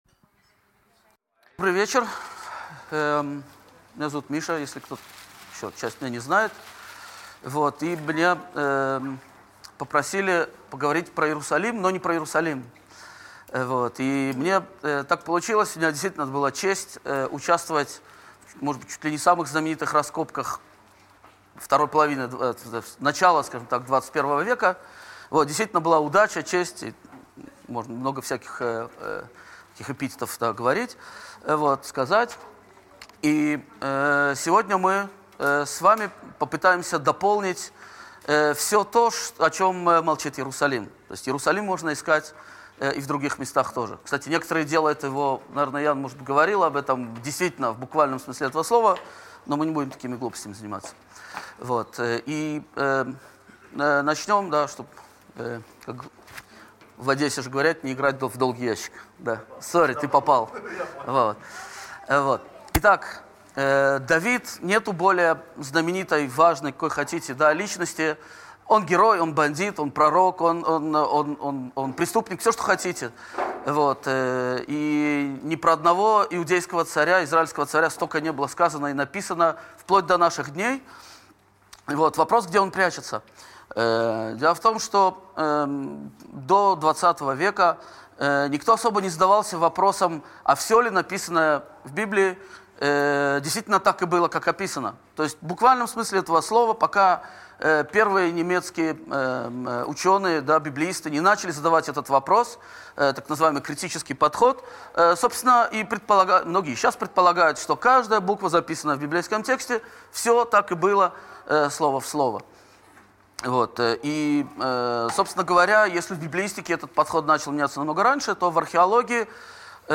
Аудиокнига Где зарыты ключи к истории древнего Иерусалима?